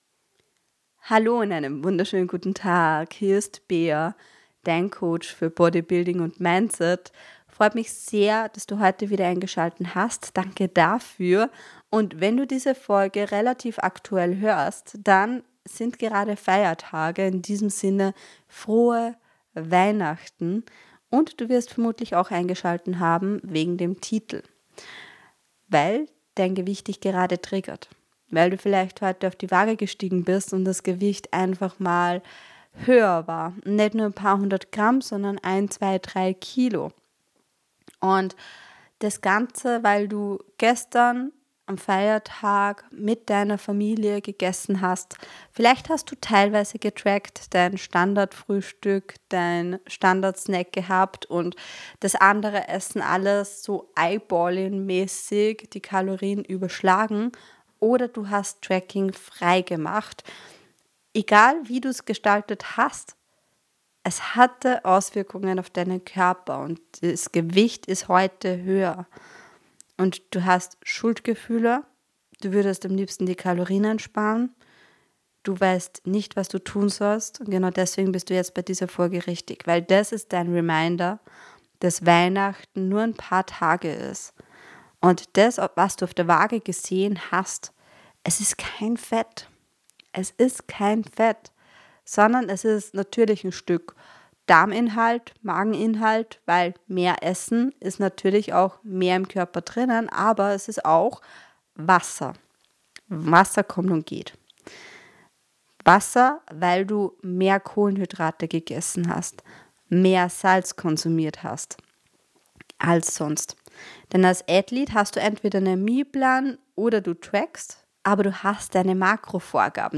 (Pep-Talk) ~ Strong Passion Podcast